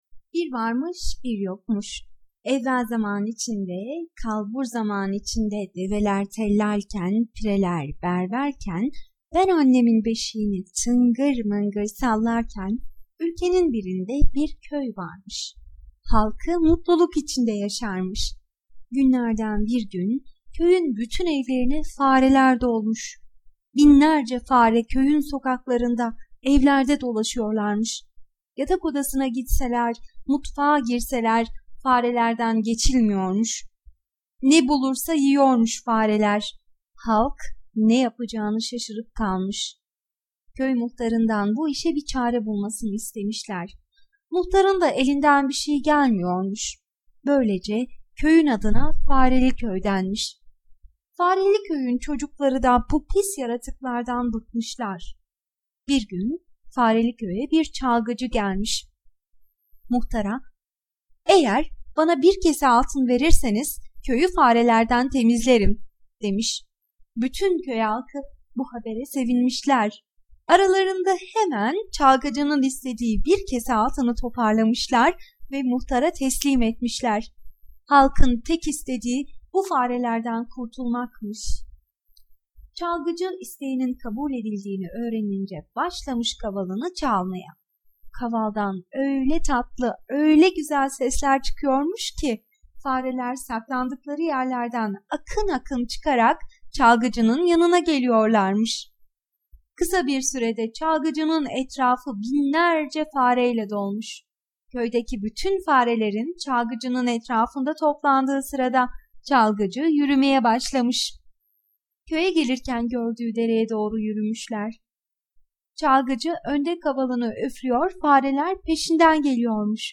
Fareli köyün kavalcısı sesli masalı mp3 sitemize eklenmiştir.
Kategori Sesli Çocuk Masalları